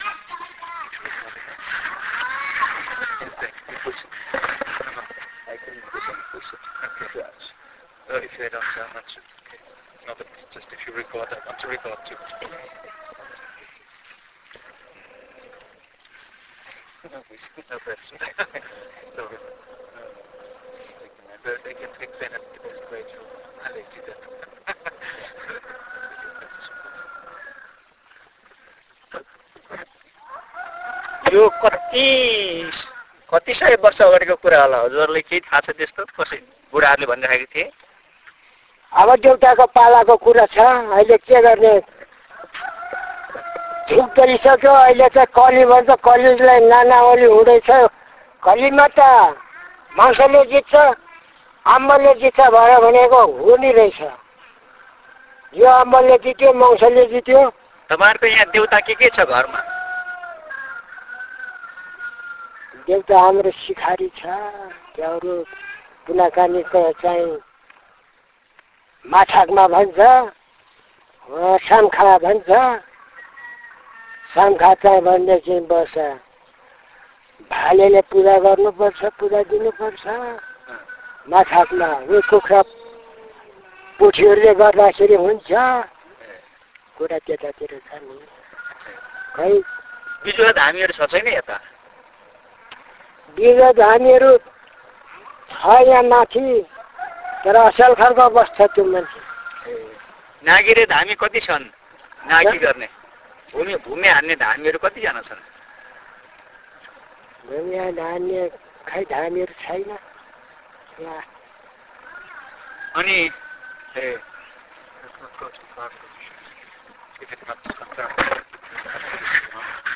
Country: Nepal
Location: Chichinga